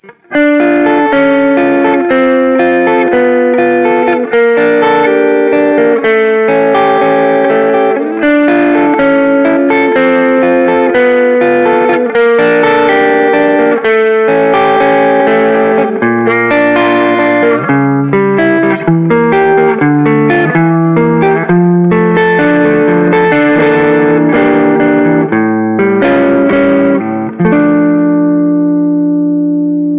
Breakup
RAW AUDIO CLIPS ONLY, NO POST-PROCESSING EFFECTS